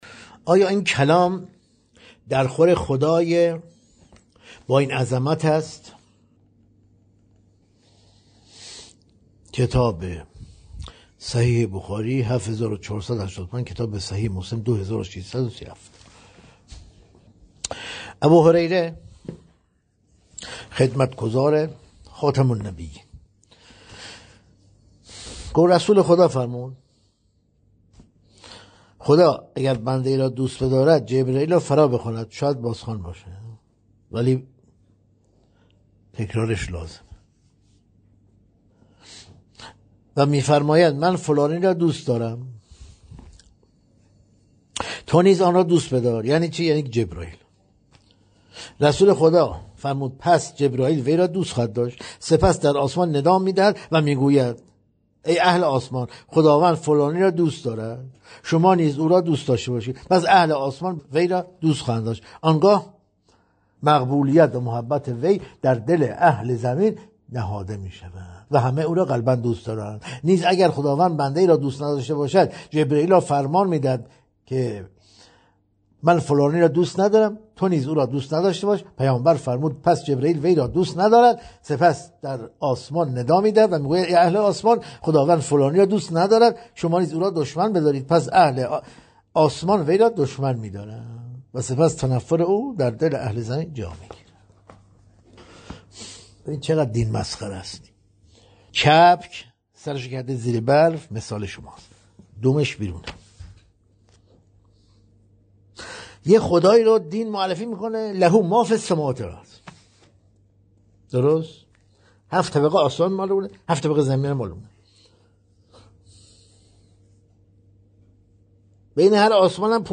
در این بخش، می‌توانید گزیده‌ای از تدریس‌های روزانه بروجردی، کاشف توحید بدون مرز، را مطالعه کرده و فایل صوتی آن را بشنوید.